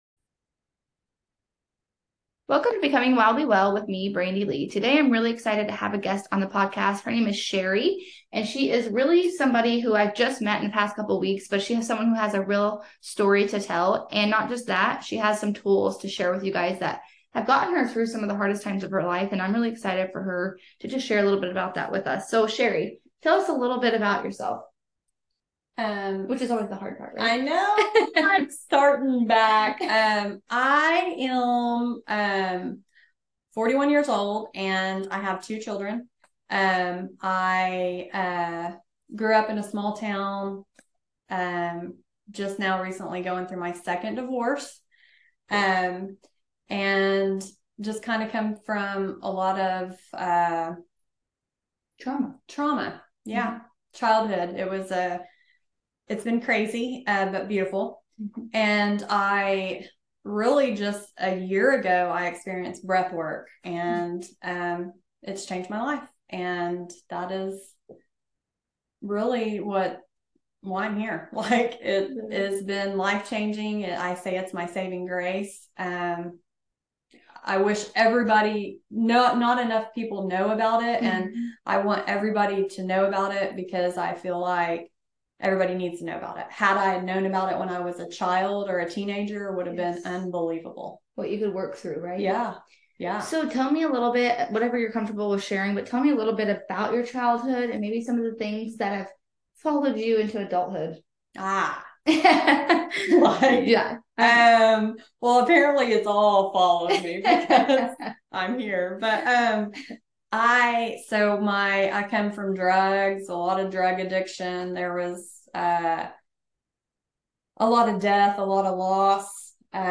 Interview Session